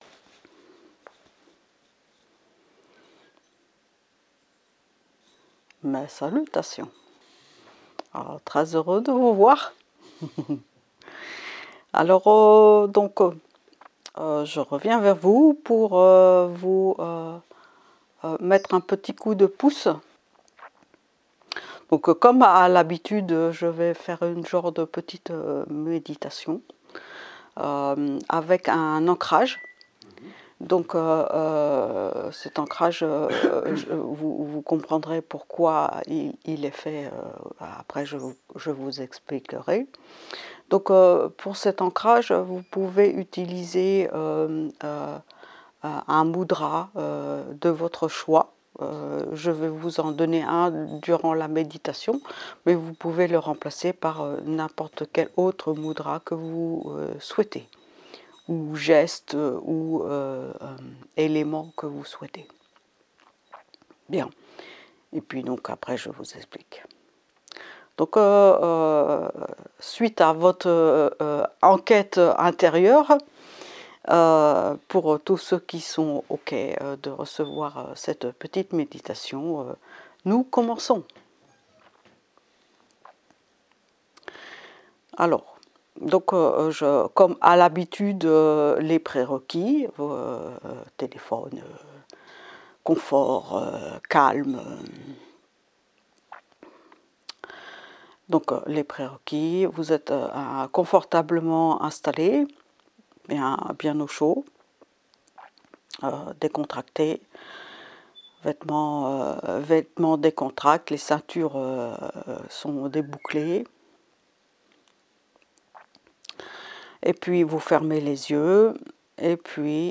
Ces méditations ou introspections sont des enregistrements canalisés en direct